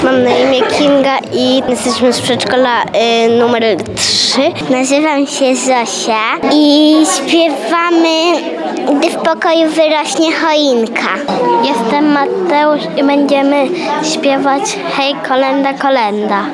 Kolędowały przedszkolaki
Po raz 13. odbył się w Przedszkolu nr 4 z Oddziałem Integracyjnym w Suwałkach przegląd kolęd i pastorałek.
W kolorowych przebraniach zaśpiewały bożonarodzeniowe piosenki.